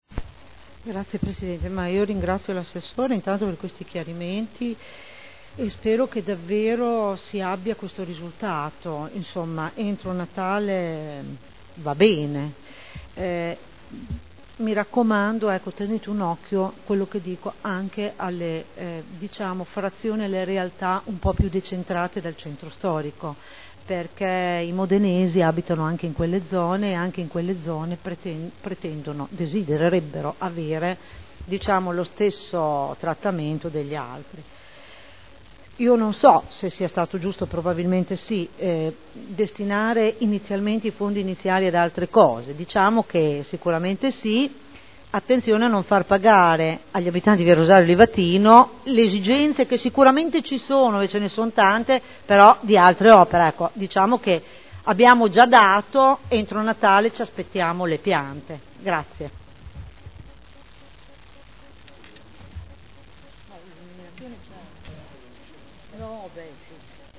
Cinzia Cornia — Sito Audio Consiglio Comunale